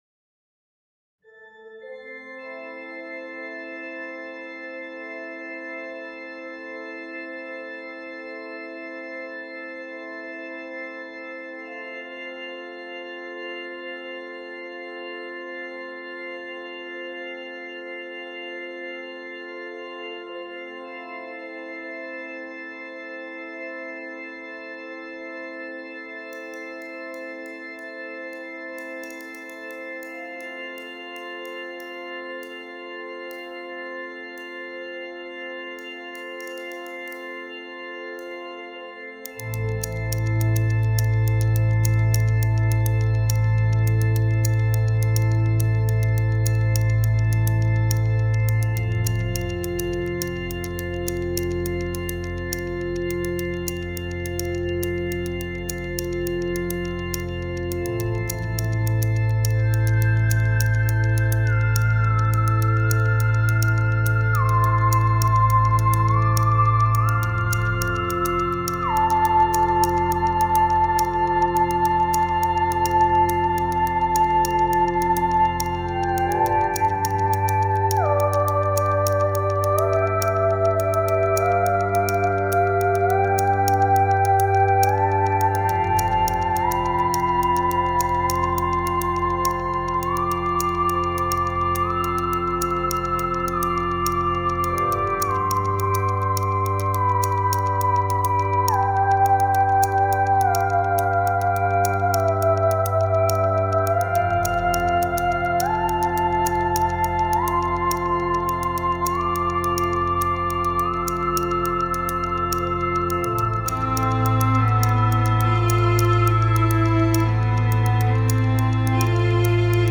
performance instrumentale